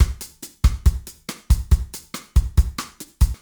Drum Loops
Bossa 3
Straight / 140 / 2 mes
BOSSA1 - 140.mp3